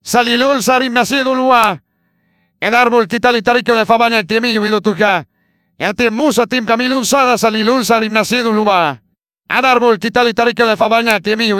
Fakeyou se llama.
Acá Mariano Closs recitando el Corán